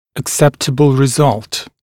[ək’septəbl rɪ’zʌlt][эк’сэптэбл ри’залт]приемлемый результат